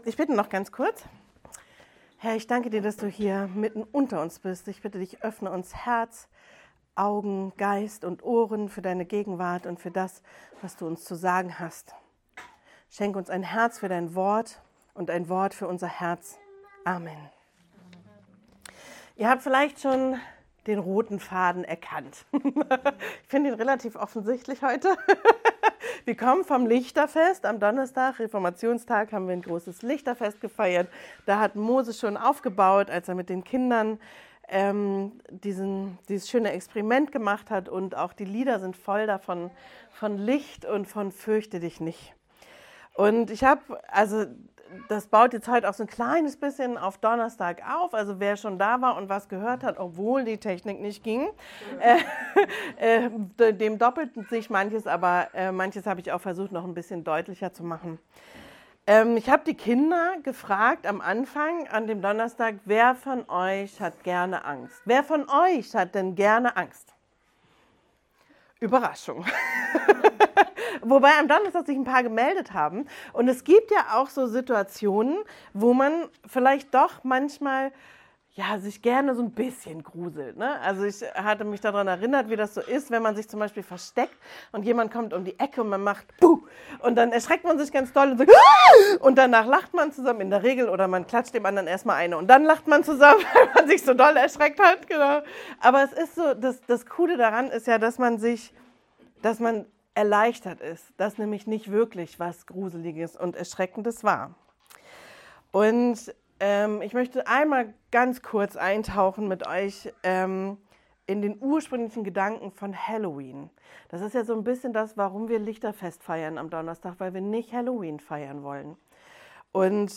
Predigtpodcast